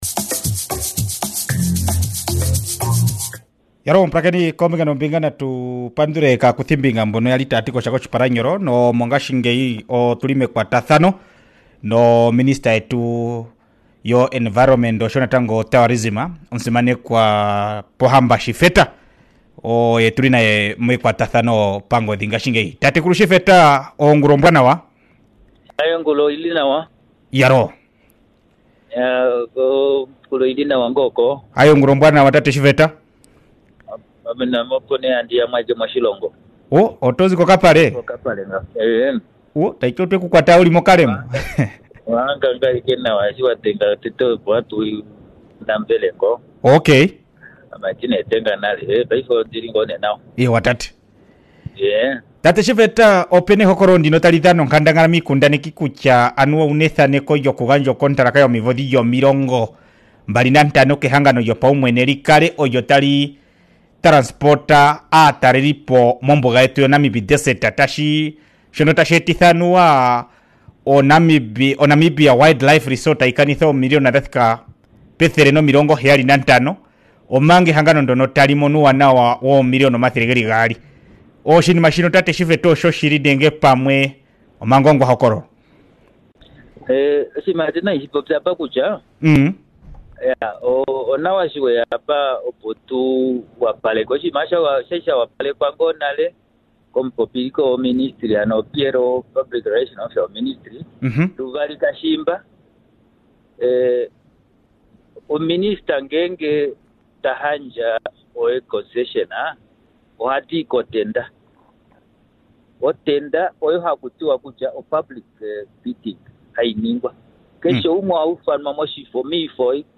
24 Apr interview with Minister of environment and tourisms Hon. Pohamba Shifeta.